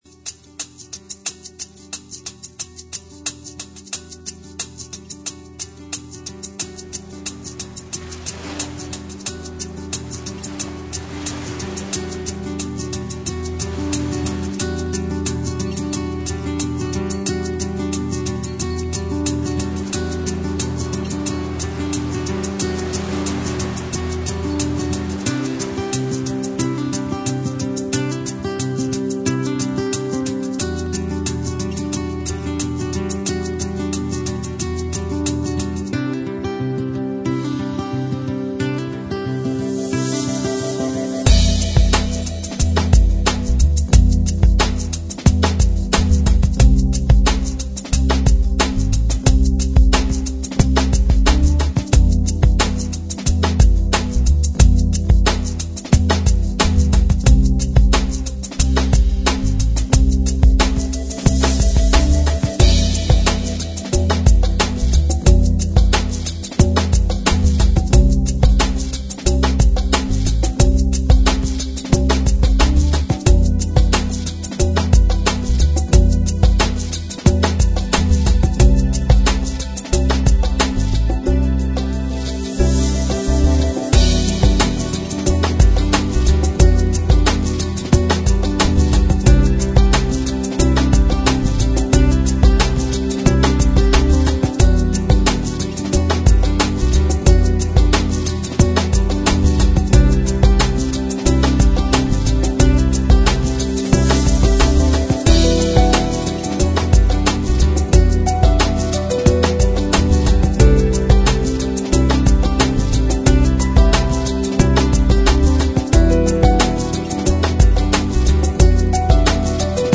Avis aux amateurs de musique d'ambiance :wink:
J'adore le piano à la fin...